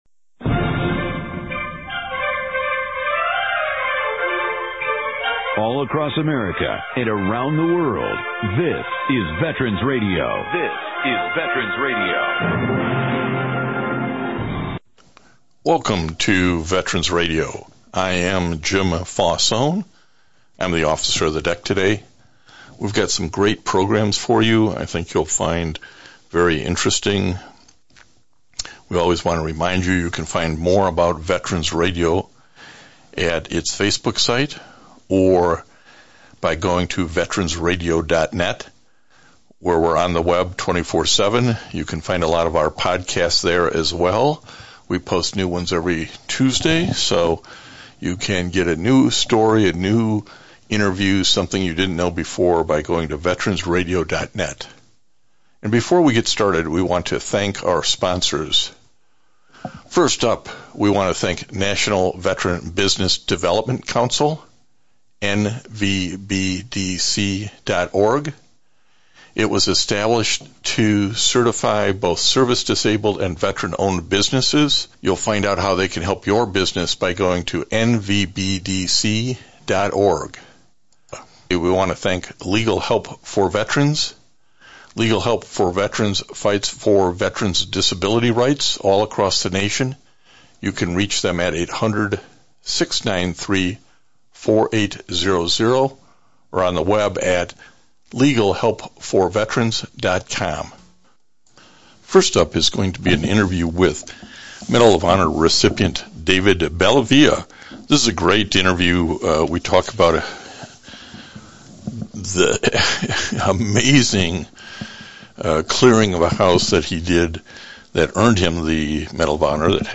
MOH Recipient David Bellavia shares the story and the "glory".